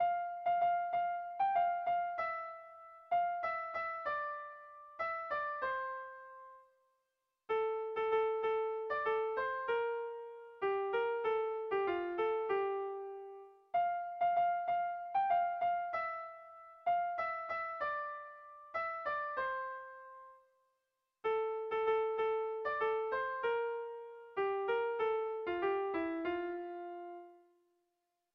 Kontakizunezkoa
Zortziko ertaina (hg) / Lau puntuko ertaina (ip)
ABAB